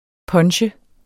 Udtale [ ˈpʌnɕə ]